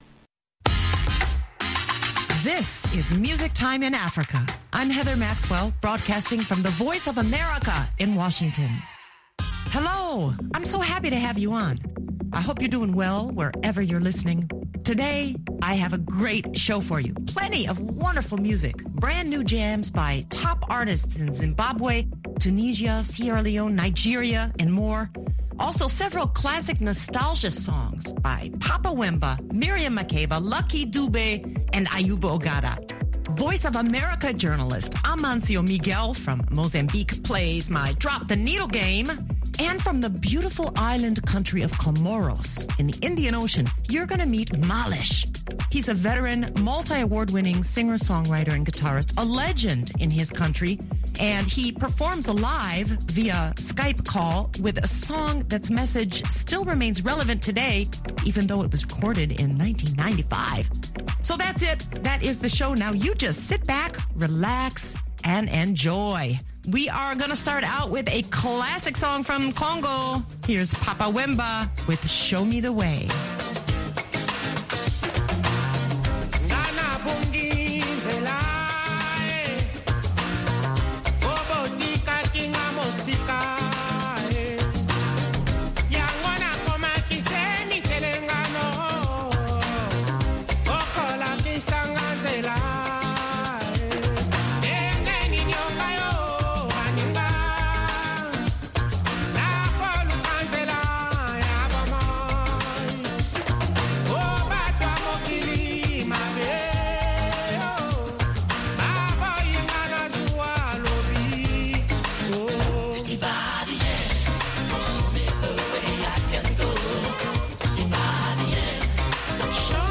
Conversation and Live Music with Comorian Musician Maalesh